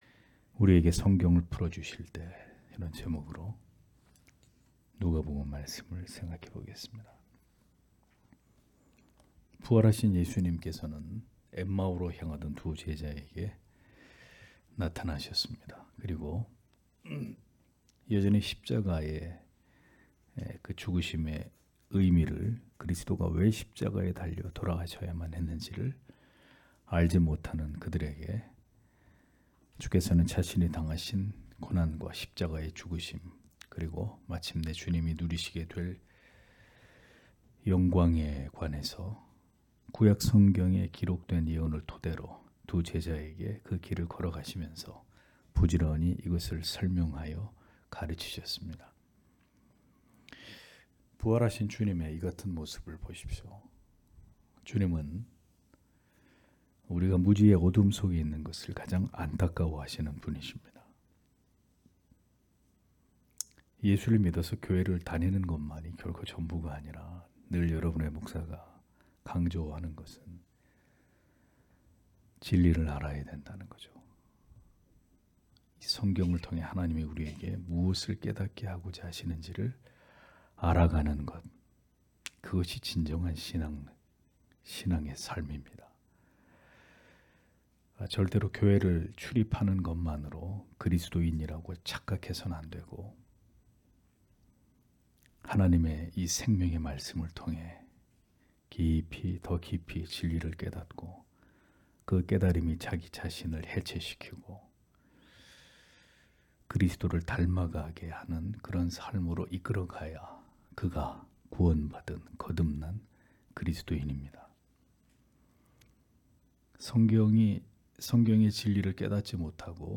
금요기도회 - [누가복음 강해 185] '우리에게 성경을 풀어주실 때' (눅 24장 28- 35절)